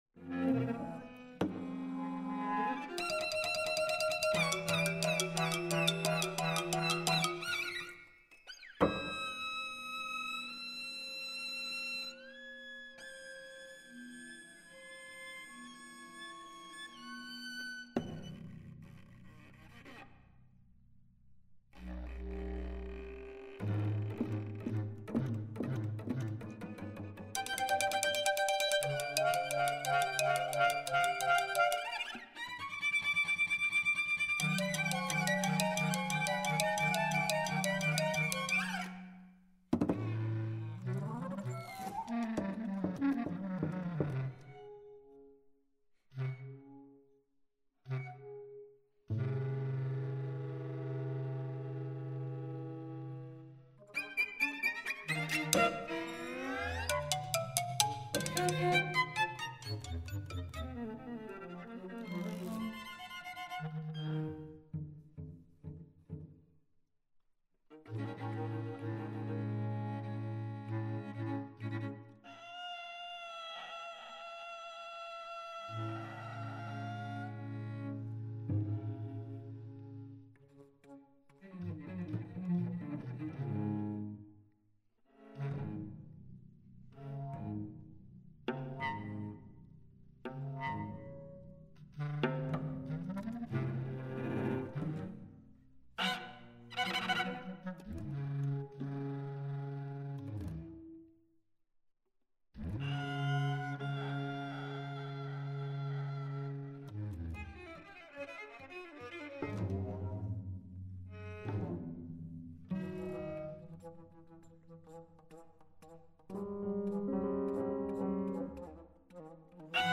for speaker and 7 instruments (2002/2007)
speaker
flute (C-flute, piccolo, bass flute)
percussion (marimbaphone, vibraphone, tamtam, bass drum)
piano
violin
viola
violoncello